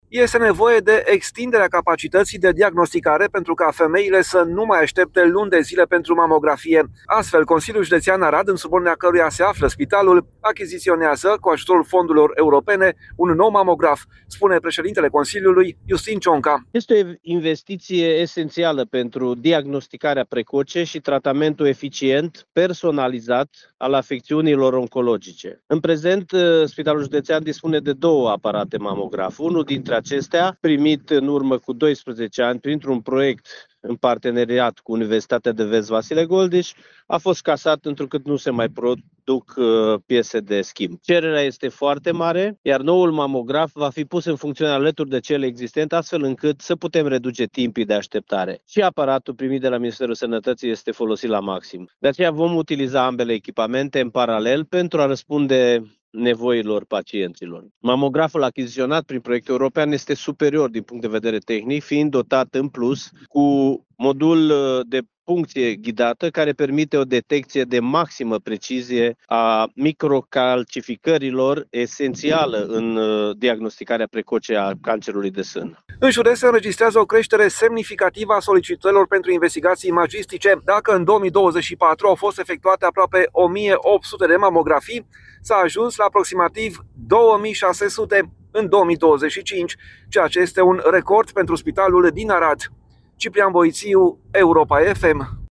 Astfel, Consiliul Județean Arad, în subordinea căruia se află spitalul, achiziţionează un nou mamograf cu ajutorul fondurilor europene, spune preşedintele Consiliului, Iustin Cionca.
Preşedintele CJ Arad, Iustin Cionca: Cererea pentru mamografii este foarte mare